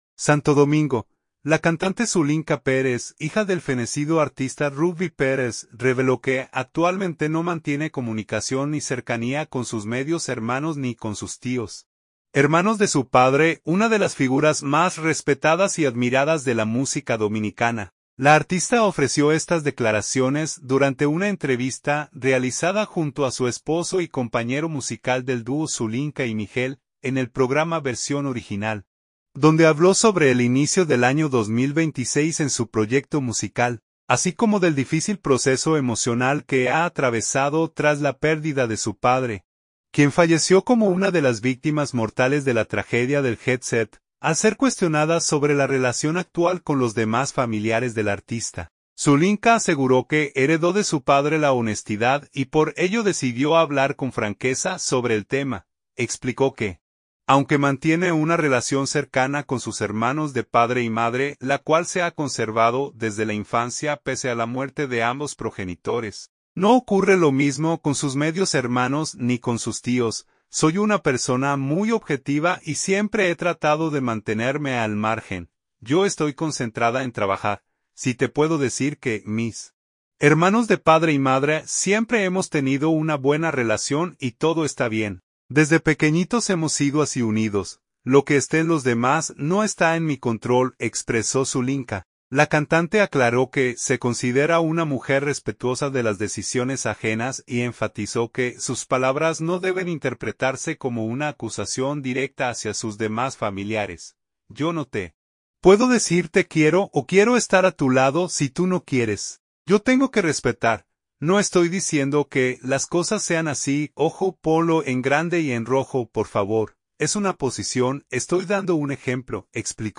La artista ofreció estas declaraciones durante una entrevista